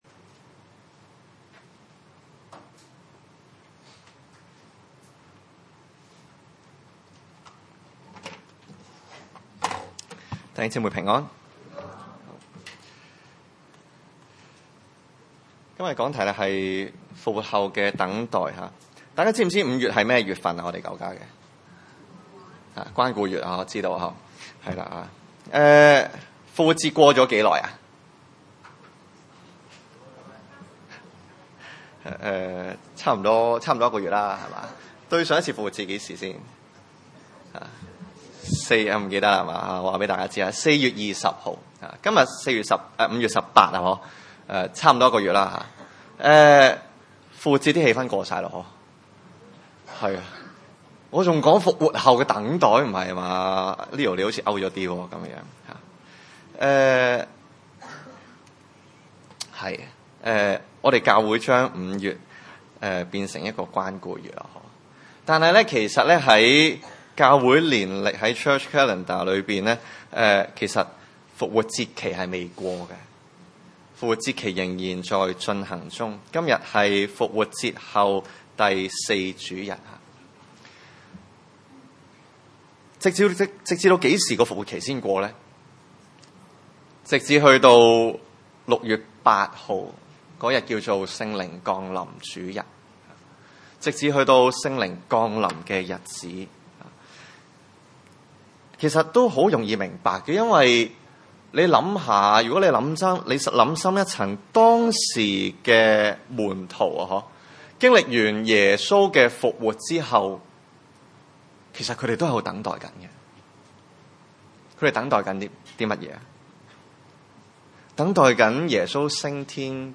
31-35 崇拜類別: 主日午堂崇拜 31 他既出去，耶穌就說：「如今人子得了榮耀，神在人子身上也得了榮耀。